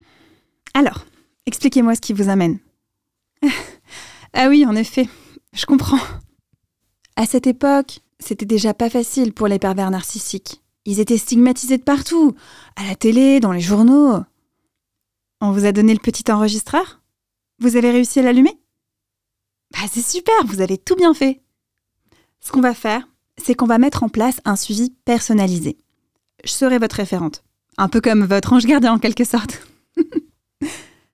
Voix off
Voix douce, jeune, fraîche, souriante, médium.
10 - 40 ans - Mezzo-soprano